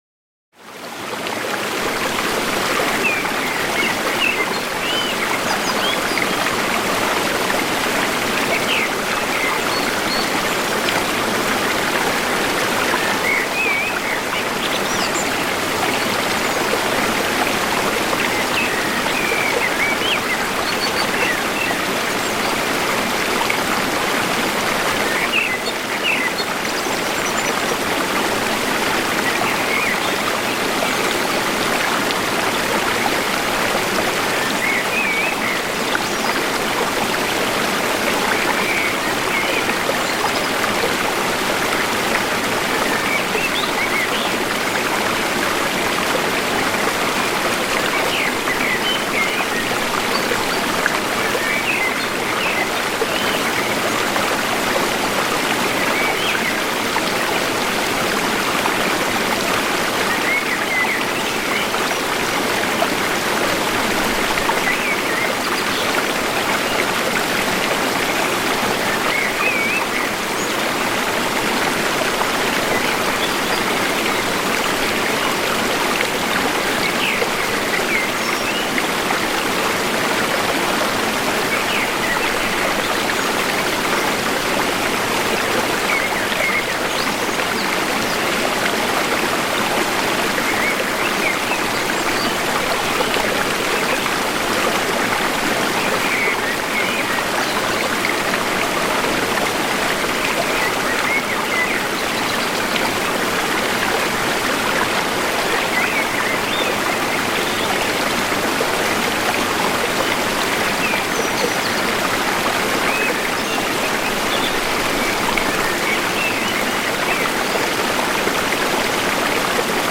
NATURGEWALT-RUHE-VEREINIGUNG: Stürzender Wasserfall-Balance